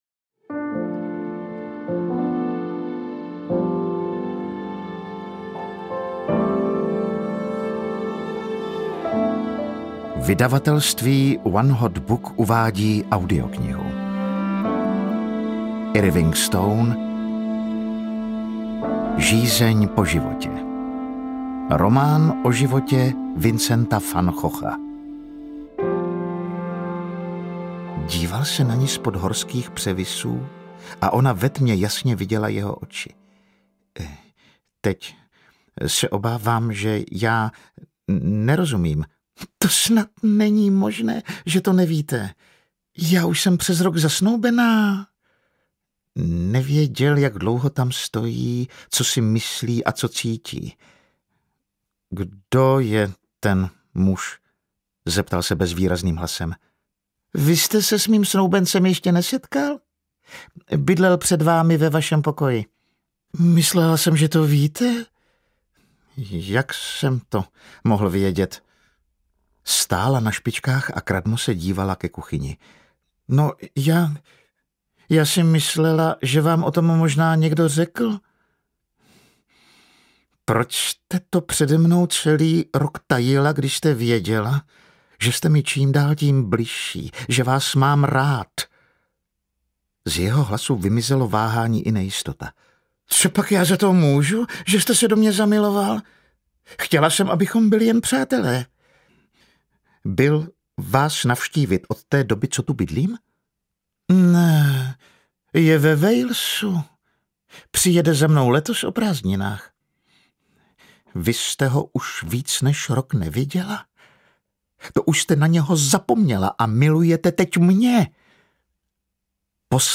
Žízeň po životě audiokniha
Ukázka z knihy